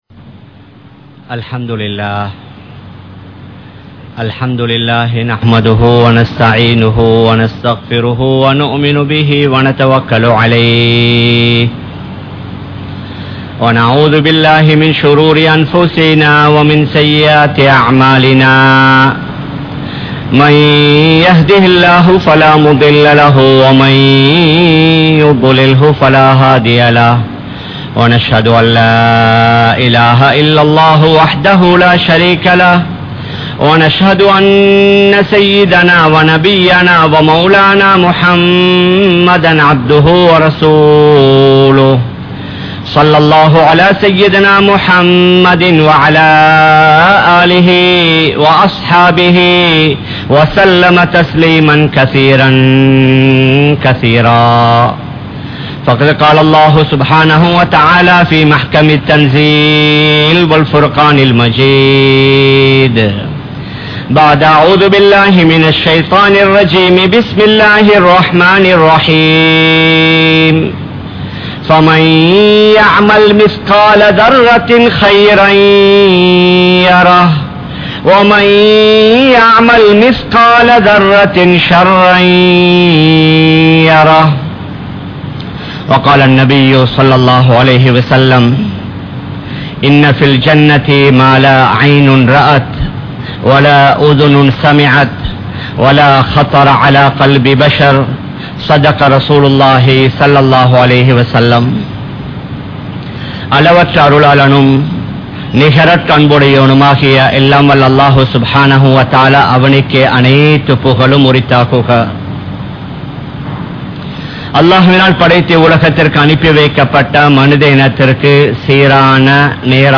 Suvarkaththin Vaalkai (சுவர்க்கத்தின் வாழ்க்கை) | Audio Bayans | All Ceylon Muslim Youth Community | Addalaichenai
Kollupitty Jumua Masjith